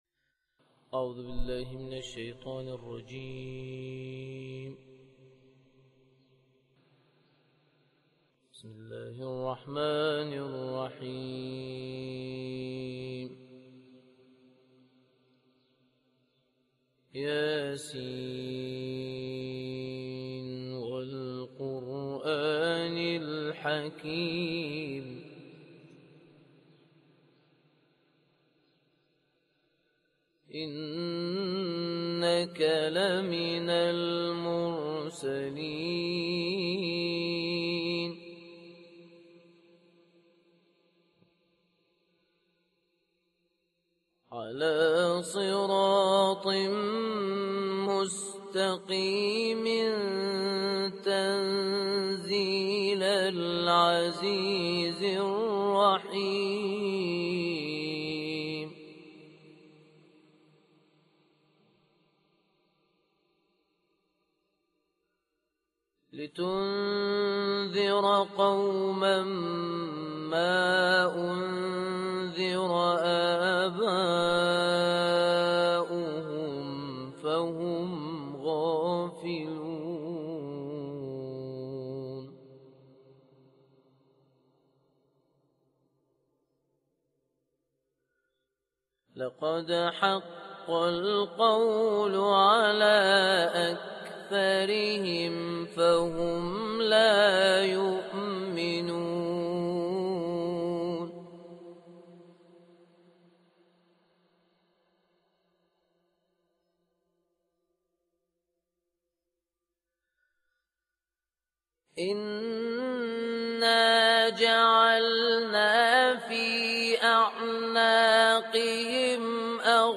الدرس الثاني - تطبيق على سورة يس - لحفظ الملف في مجلد خاص اضغط بالزر الأيمن هنا ثم اختر (حفظ الهدف باسم - Save Target As) واختر المكان المناسب